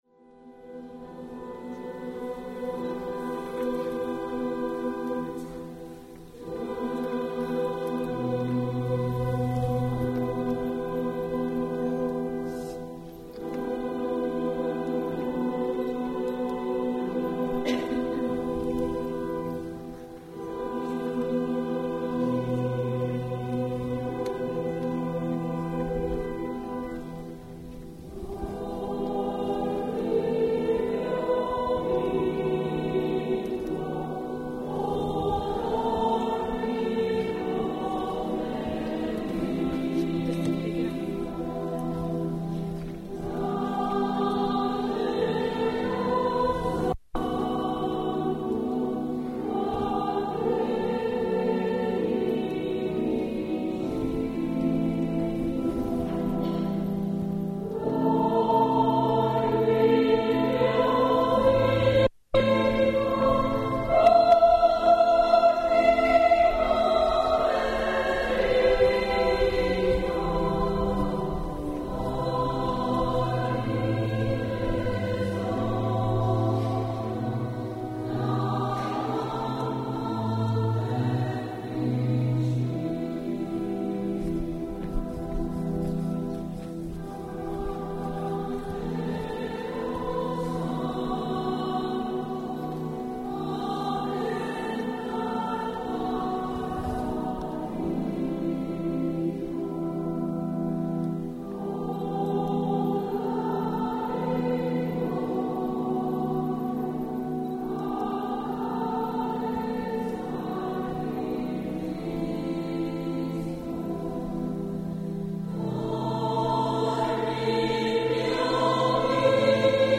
Quarta Rassegna di Musica Sacra presso il Duomo di Barga
Corale Femminile Duomo di Barga
Duomo di Barga singing "Ninna Nanna" (canto popolare barghigiano)
ninna_nanna.mp3